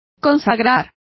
Complete with pronunciation of the translation of dedicated.